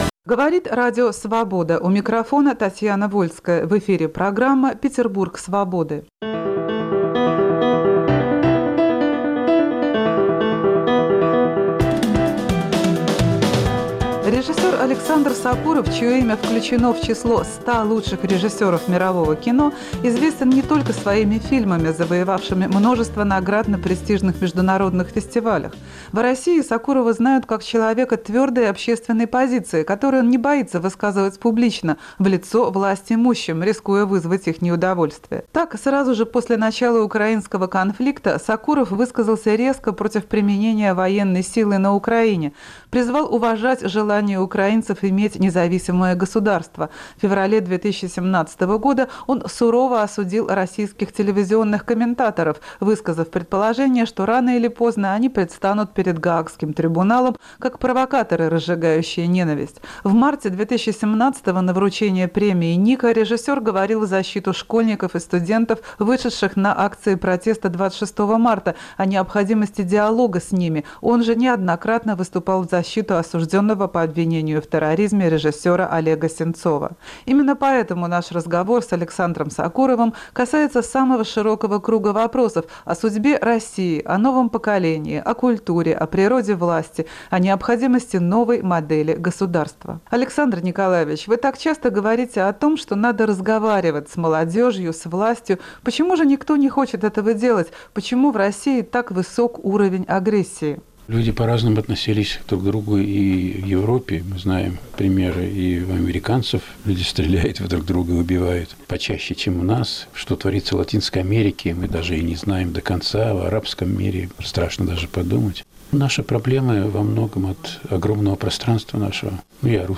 Наш разговор с Александров Сокуровым касается самого широкого круга вопросов – о судьбе России, о новом поколении, о культуре, о природе власти, о необходимости новой модели государства.